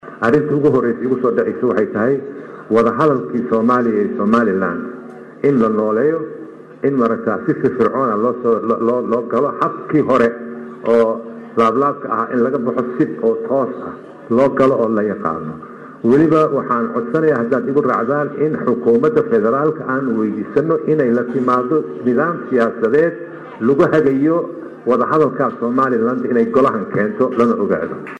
Isagoo ka hadlayay  xiritaanka Kalfadhigii labaad ee Golaha shacabka baarlamaanka Soomaliya ayuu sheegay Jawaari in loo baahan yahay in laga mira dhaliyo wada-hadalka Soomaaliya iyo Soomaaliland,madaama lagu guuldareystay sanadihii dambe.
Hoos ka Dhageyso Codka Jawaari
Cod-Jawaari.mp3